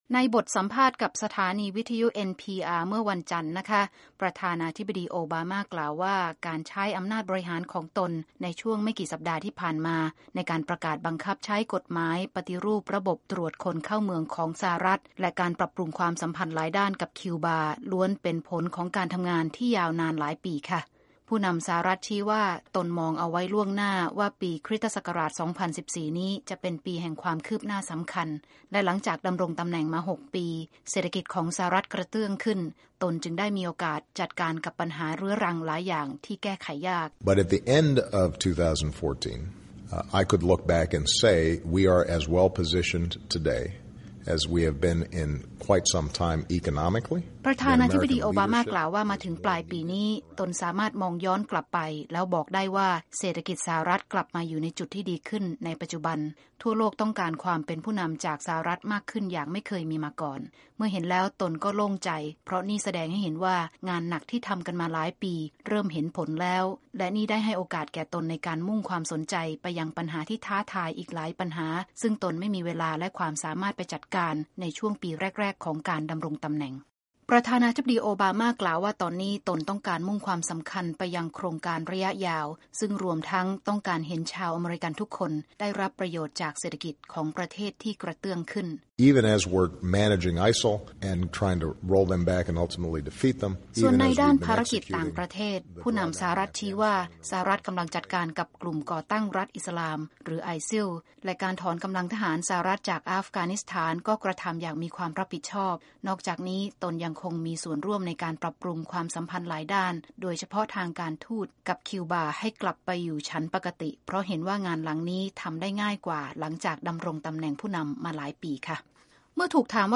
President Obama interview with National Public Radio
Obama Interview